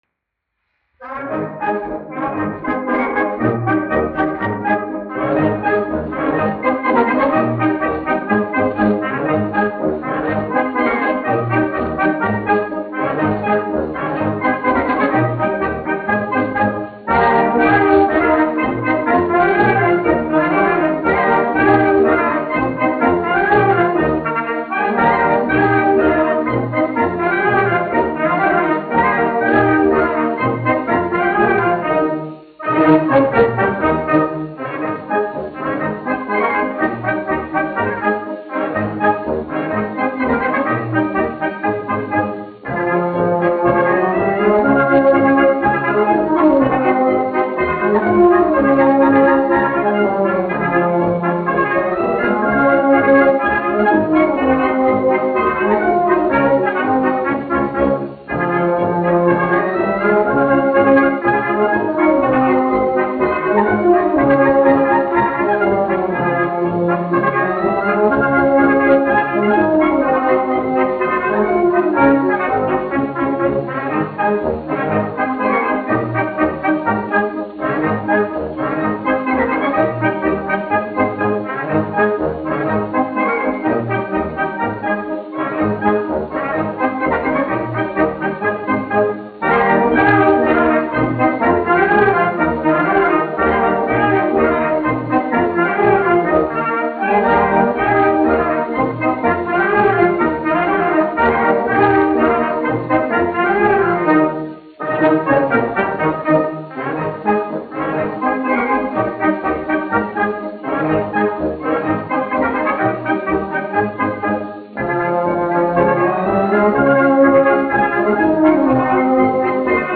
1 skpl. : analogs, 78 apgr/min, mono ; 25 cm
Polkas
Pūtēju orķestra mūzika
Skaņuplate